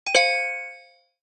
click_3.mp3